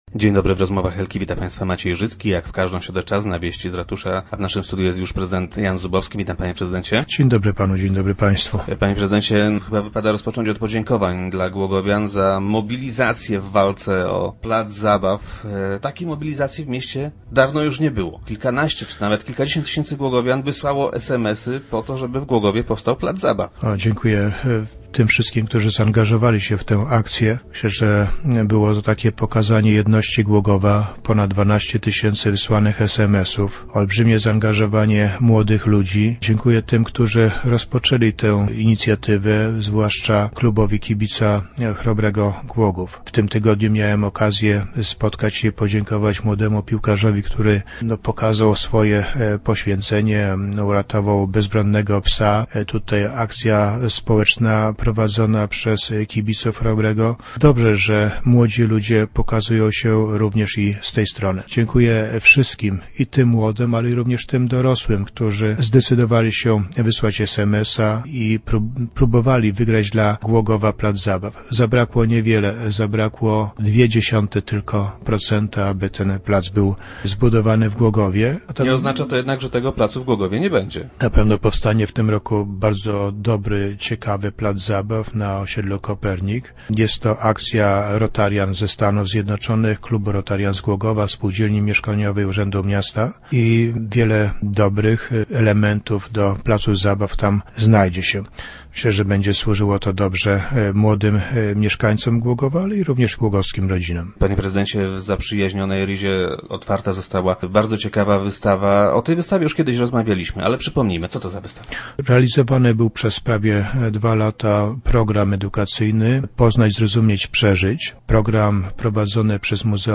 Prezydent Jan Zubowski dziękuje wszystkim, którzy wysłali SMS-y. - To był niesamowity zryw głogowian - twierdzi prezydent, który był gościem Rozmów Elki.